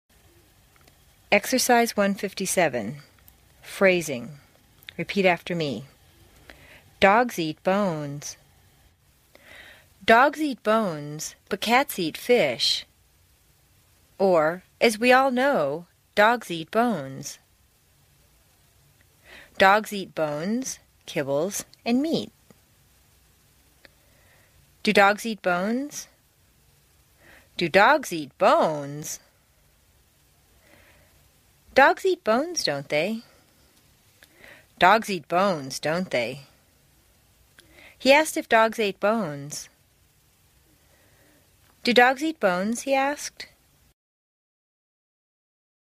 在线英语听力室美式英语正音训练第43期:Exercise 1-57 Phrasing的听力文件下载,详细解析美式语音语调，讲解美式发音的阶梯性语调训练方法，全方位了解美式发音的技巧与方法，练就一口纯正的美式发音！